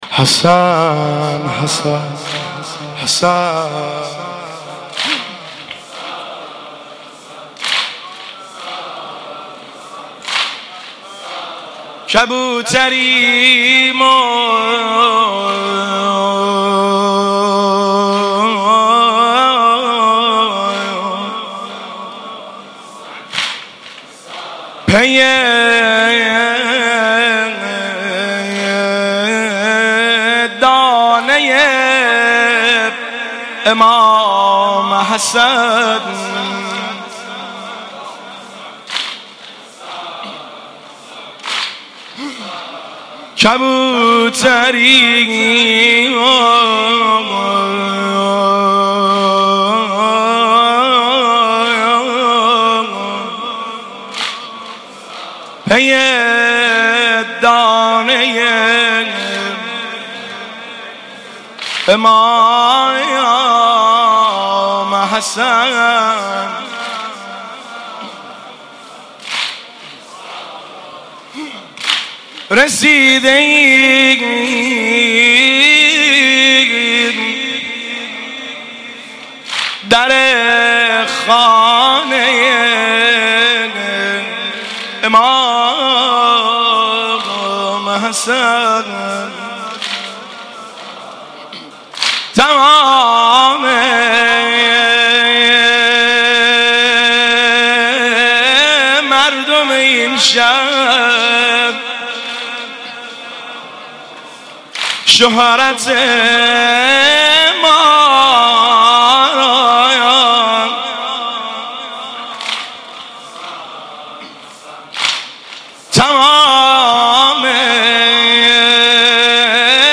شعرخوانی